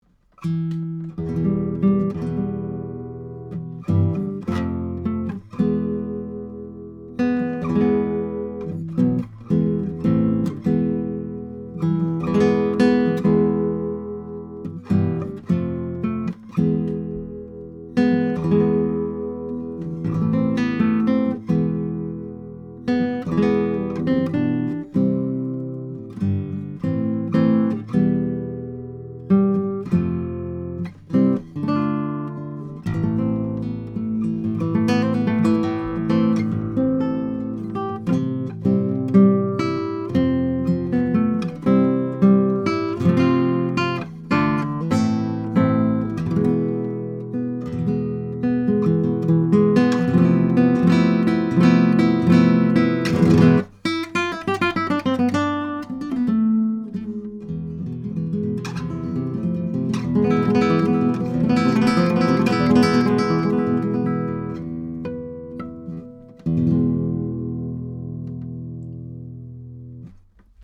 Here are a few sound files of a pair of Mini K47 on nylon 7-string guitar, going into a Trident 88 console:
K47T88Voice.mp3